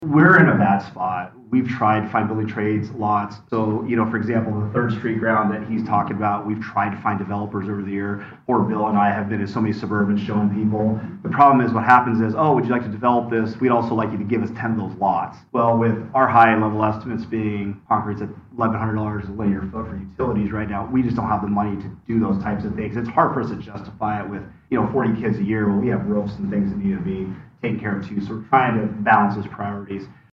The Oskaloosa City Council held a regular meeting on Monday and discussed a collaborative housing project with the Oskaloosa Community School District.